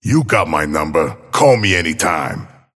Abrams voice line - You got my number. Call me anytime.